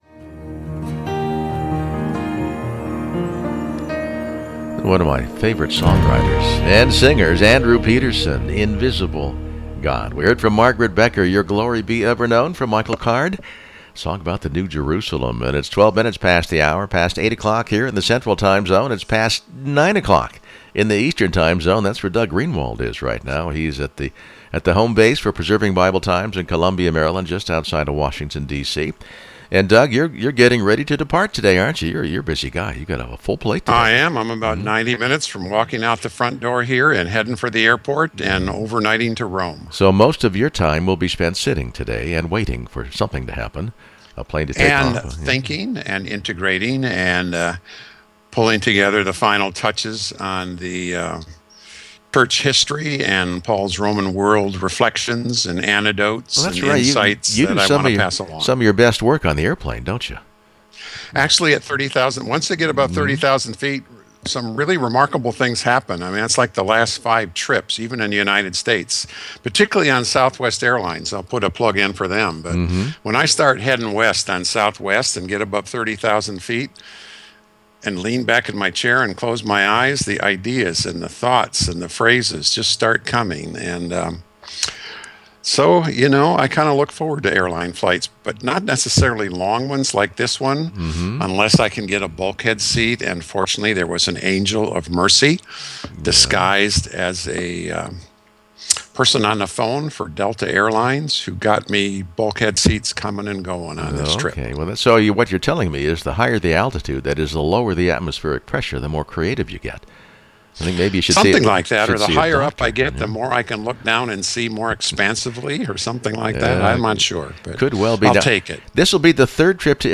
MORNING SHOW, JULY 15, 2015: More on first-century Roman biblical context …